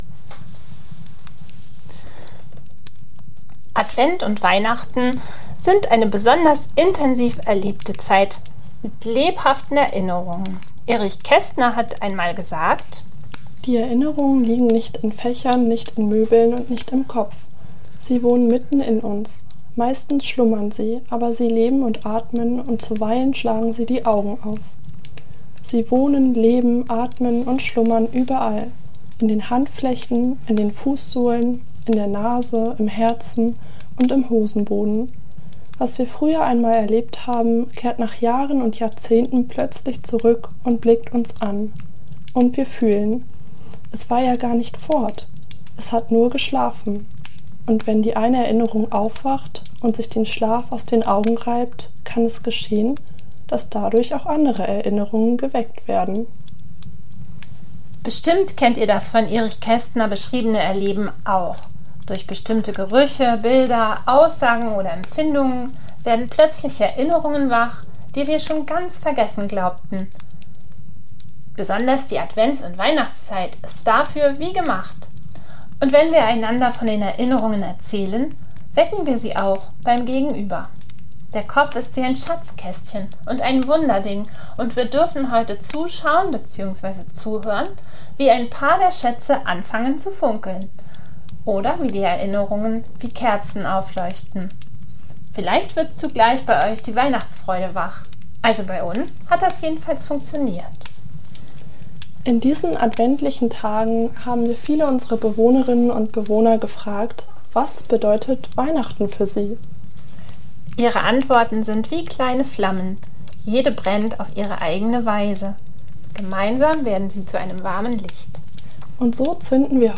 Diese Weihnachts-Sammelfolge ist wie eine Collage aus vielen Stimmen, lebendigen Erinnerungen und tiefen Gefühlen. Unsere Bewohnerinnen und Bewohner erzählen in wenigen Worten, was Weihnachten für sie bedeutet.